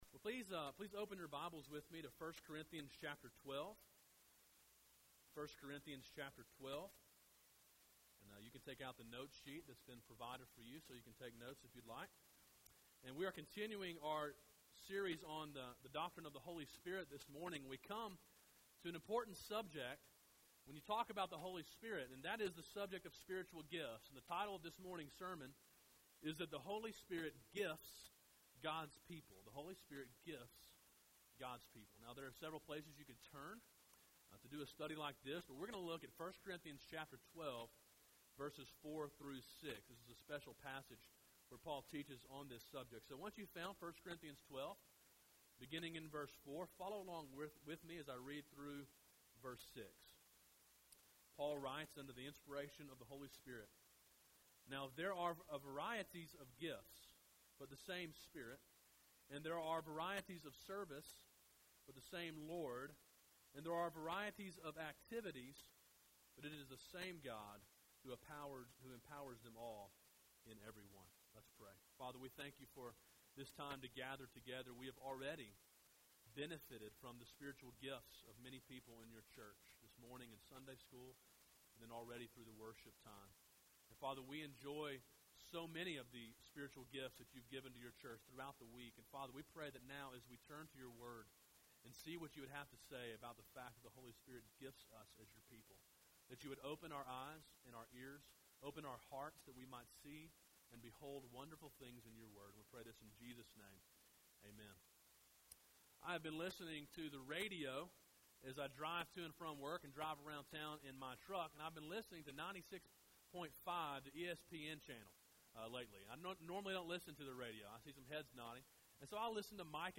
A sermon in a series on the Holy Spirit. Preached during the morning service on 9.23.12. Download mp3